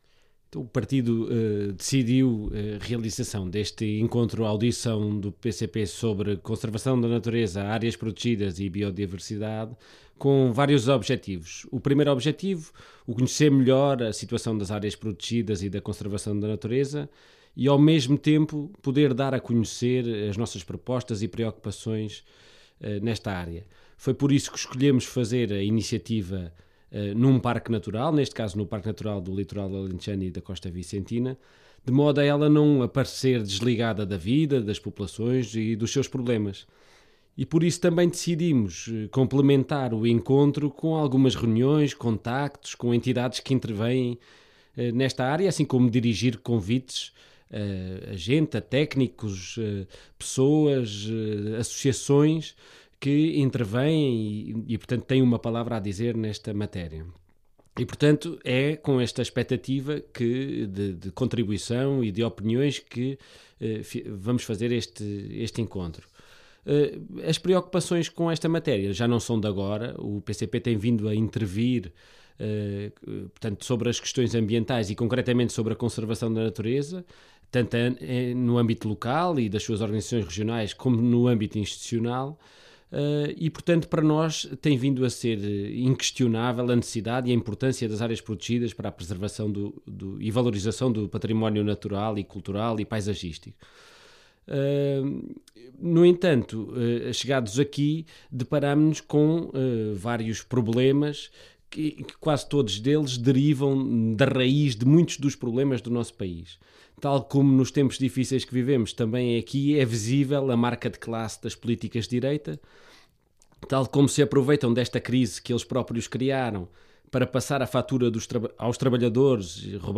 Declaração